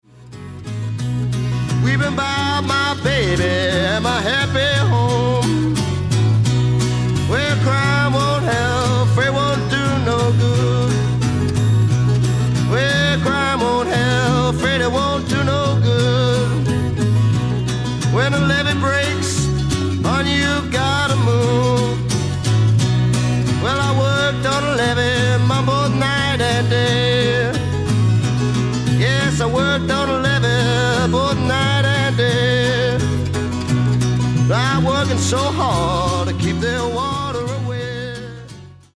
BLUES ROCK / COUNTRY BLUES